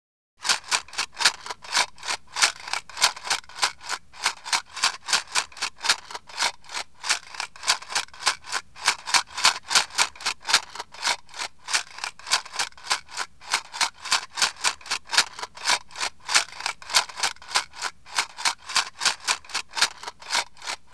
shake_p.wav